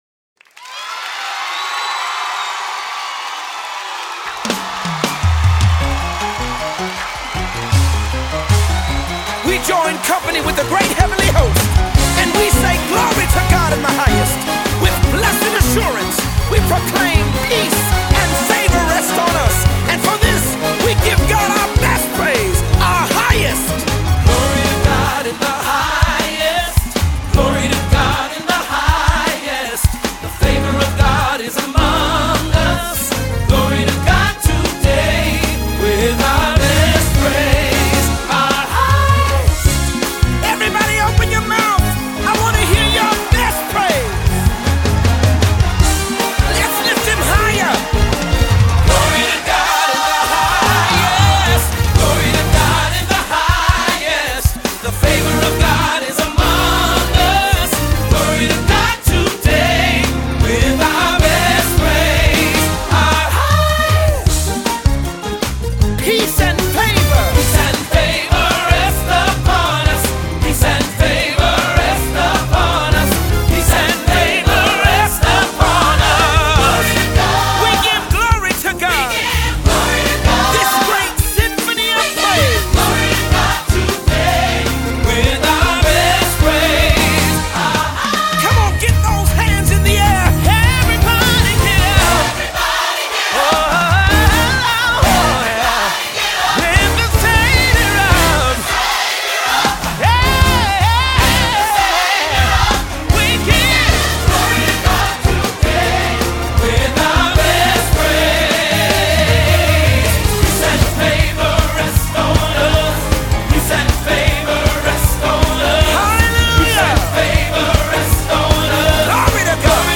good 'ole church music
soaring choir-friendly melodies